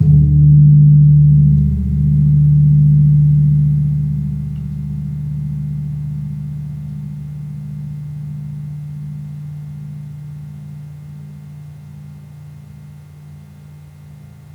Gong-D1-p.wav